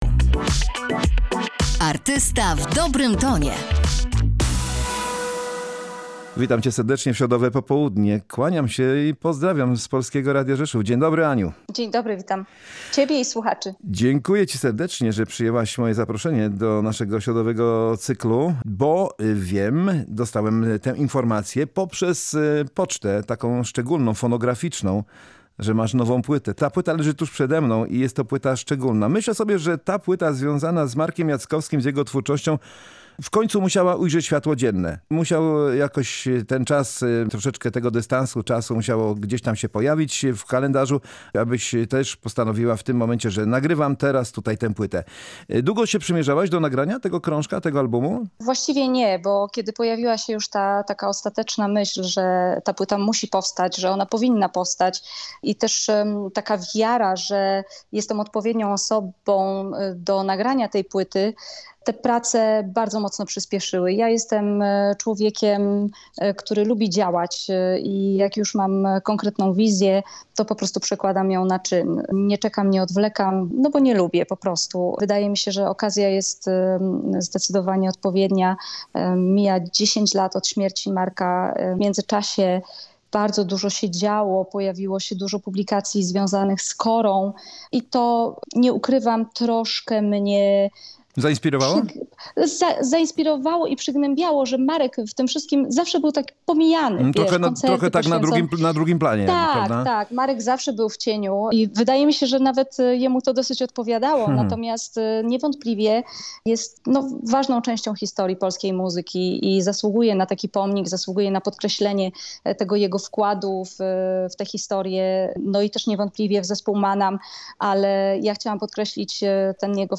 10. rocznica śmierci Marka Jackowskiego stała się inspiracją dla Ani Wyszkoni do nagrania „Wyszkoni 10 Jackowski”, albumu z piosenkami tego znanego artysty w nowych, ciekawych aranżacjach. Na antenie Polskiego Radia Rzeszów piosenkarka odsłania kulisy powstawania płyty oraz wieloletniej przyjaźni ze znakomitym gitarzystą, kompozytorem i frontmana zespołu Maanam.